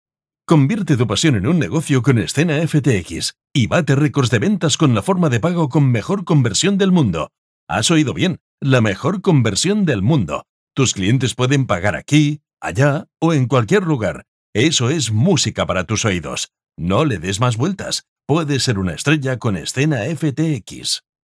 locutor de anuncios, Spanish voice talent